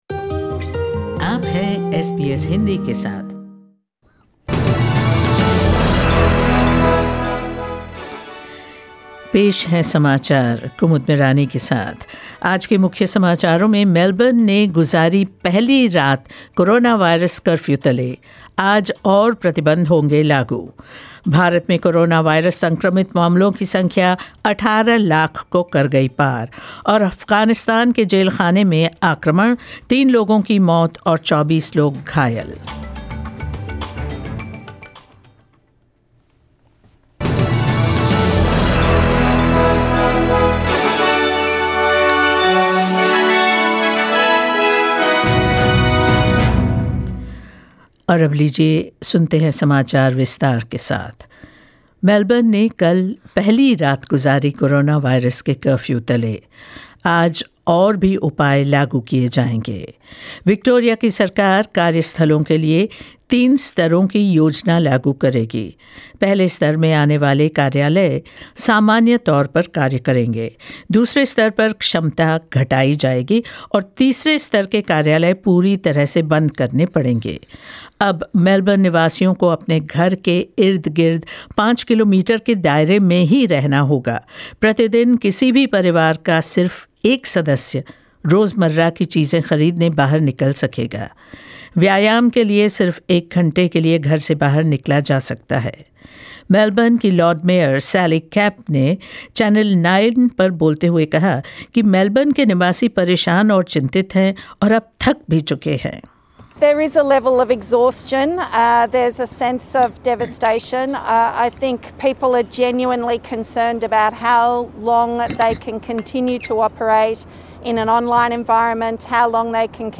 News in Hindi 03 August 2020